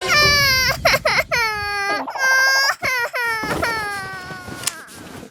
Minibods Lulu Crying 2